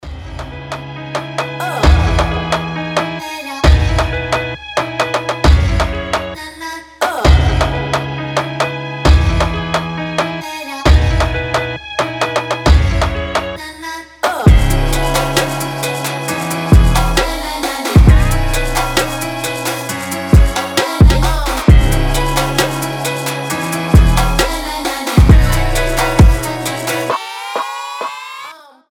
• Качество: 320, Stereo
мелодичные
пианино
Chill Trap
хор
этнические
Классный chill trap